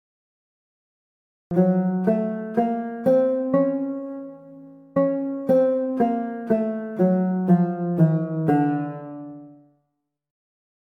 Arabic-scale_saba.mp3